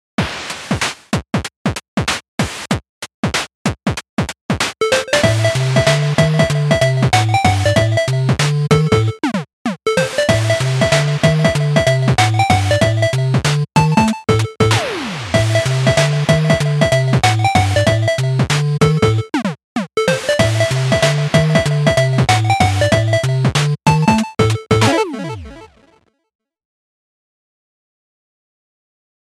チップチューンを無料ソフト音源で作ってみよう！
例3）パズルゲーっぽいイメージ
これらはすべて、次項で紹介する無料のソフト音源（YMCK Magical 8bit Plug）とプラグインエフェクト（SC BitCrusher）、そしてLogicに標準でついてくるプラグインのみで作っています。
※Logicのプラグインで使ったのは、マスタートラックのEQとリミッターのみ。